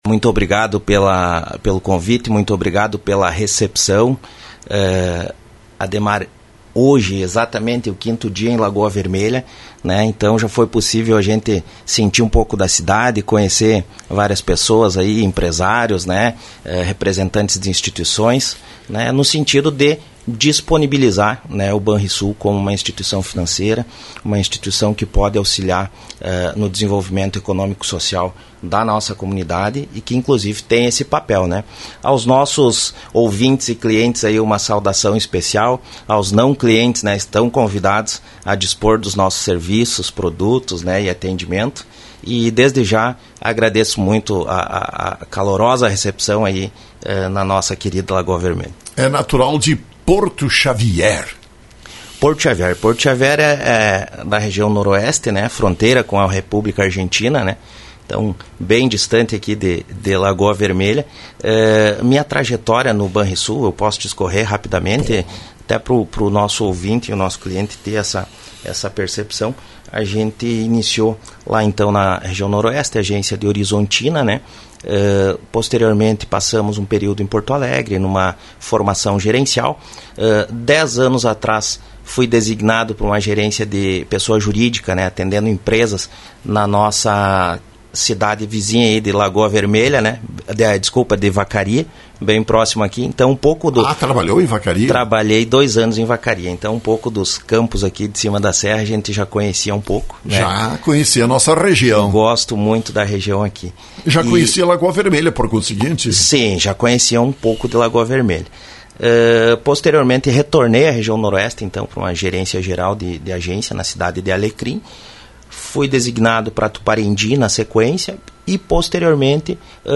entrevistado pela Rádio Lagoa FM